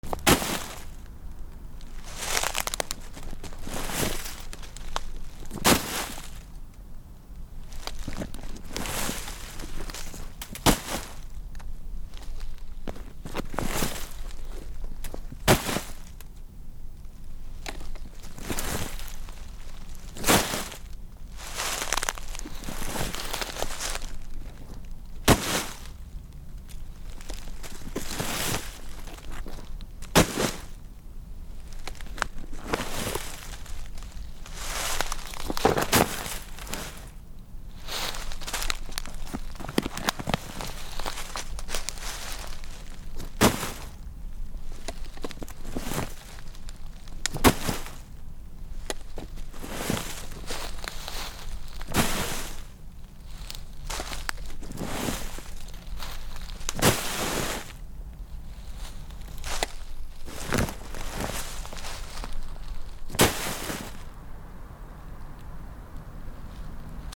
転ぶ素材 落ち葉
/ J｜フォーリー(布ずれ・動作) / J-10 ｜転ぶ　落ちる